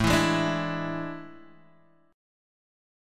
E7/A Chord